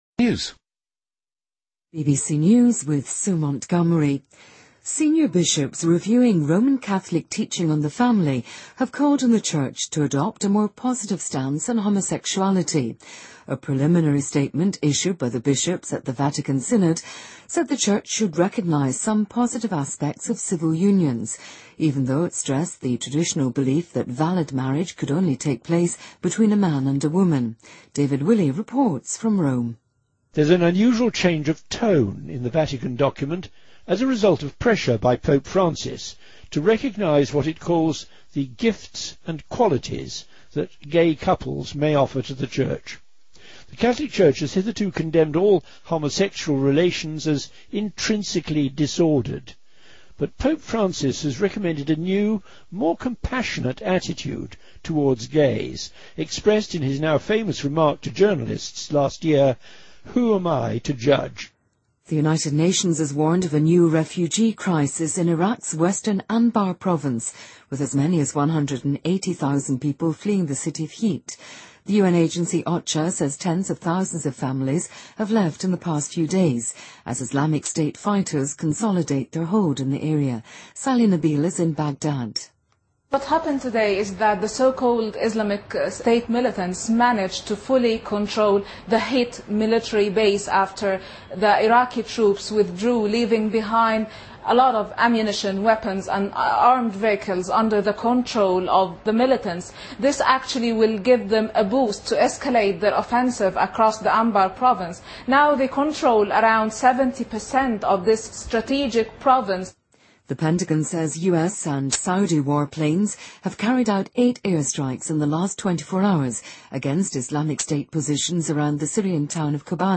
BBC news,英国议会成员就是否承认巴勒斯坦国展开讨论